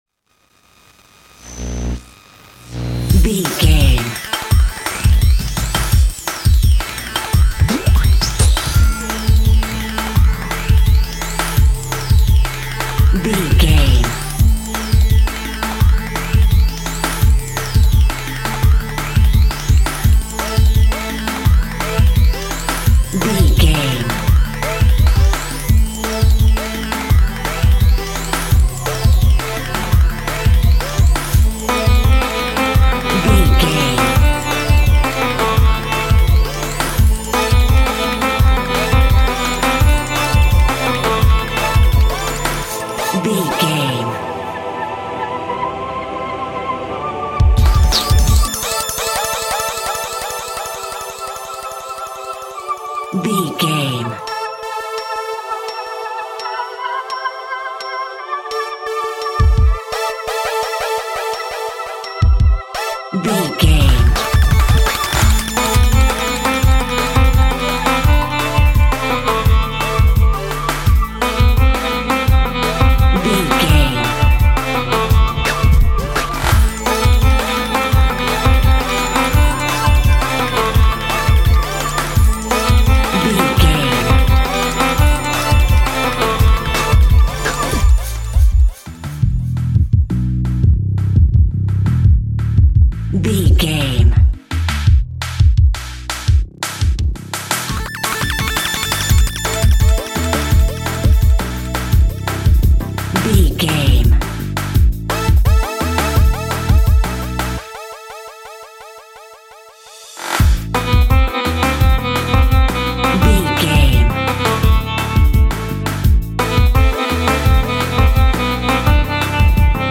Electronica at 170 BPM.
In-crescendo
Aeolian/Minor
Fast
energetic
futuristic
hypnotic
industrial
frantic
aggressive
drum machine
electric guitar
synthesiser
techno
trance